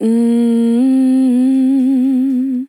Categories: Vocals Tags: DISCO VIBES, dry, EEM, english, female, fill, sample